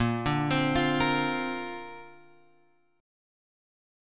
A#add9 chord {6 5 x 5 6 6} chord